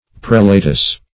Search Result for " prelatess" : The Collaborative International Dictionary of English v.0.48: Prelatess \Prel"a*tess\, n. A woman who is a prelate; the wife of a prelate.